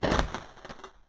unlock_door.wav